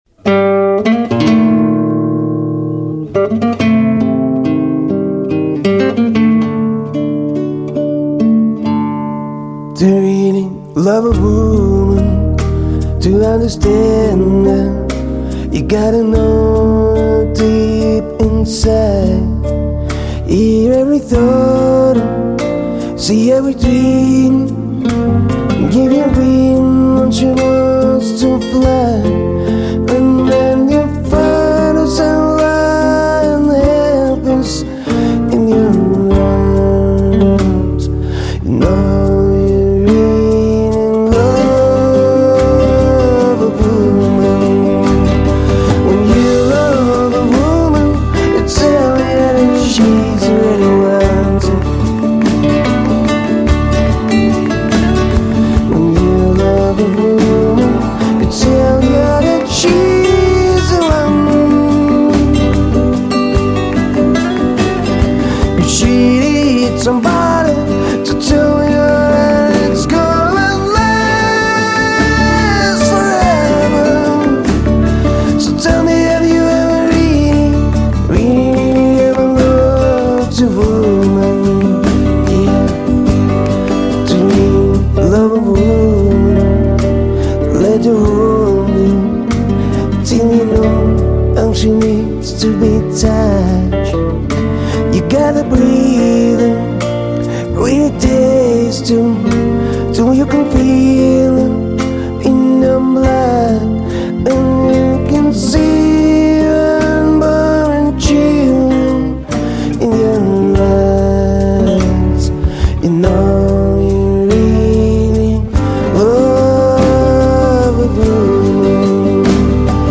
Мужской
Исполняю джаз-роковые, соул композиции.
Собственный тембр очень близок к Брайану Адамсу.
Тенор Баритон